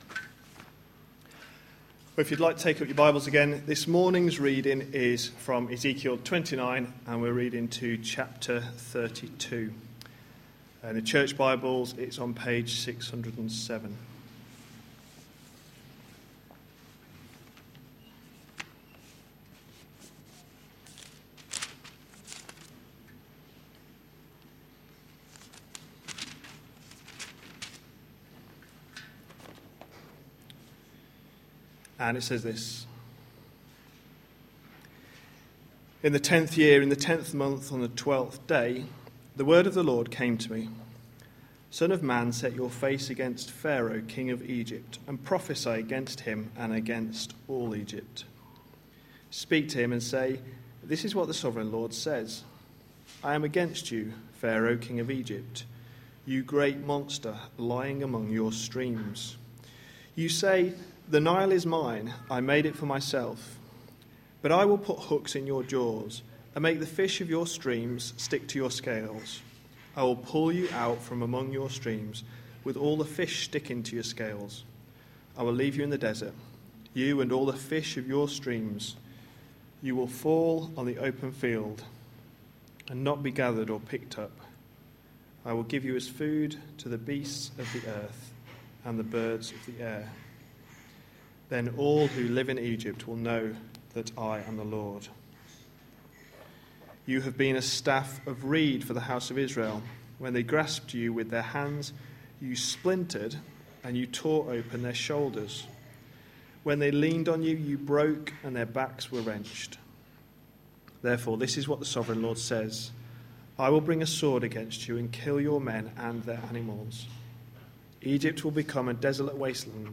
A sermon preached on 27th July, 2014, as part of our Ezekiel series.